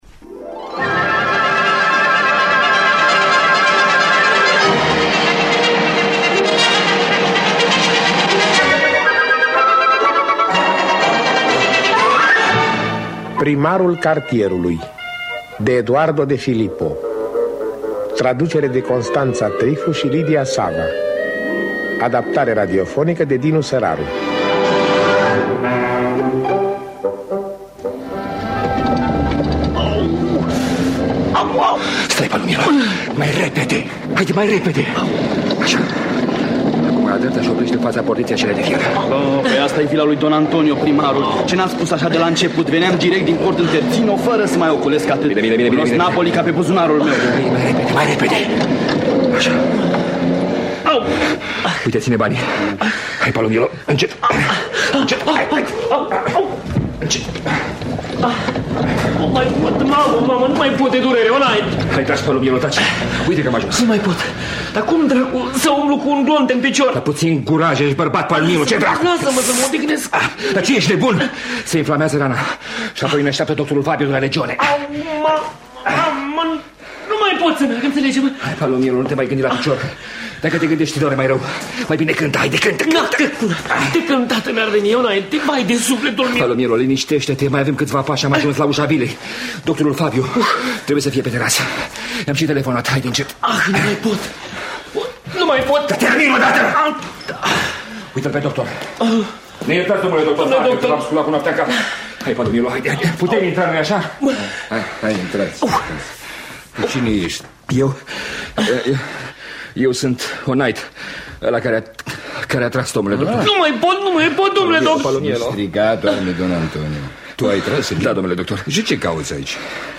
Adaptarea radiofonică de Dinu Săraru.